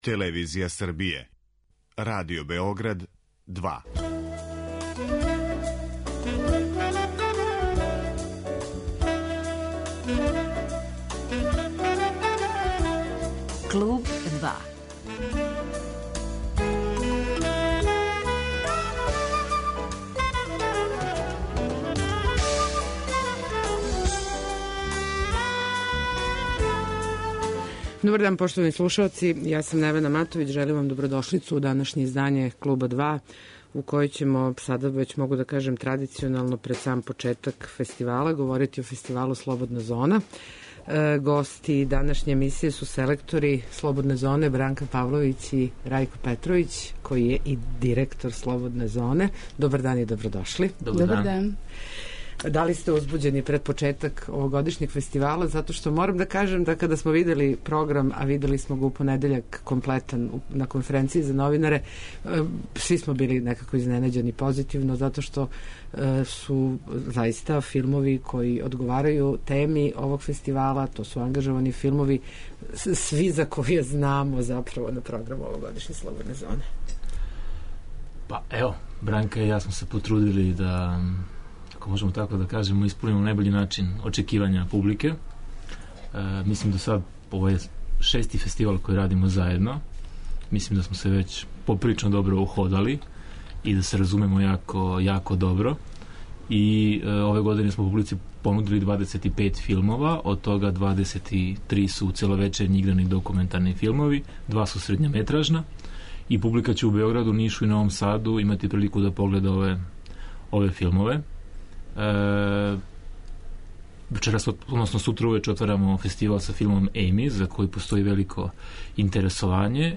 Гости данашњег 'Клуба 2' су селектори фестивала Слободна зона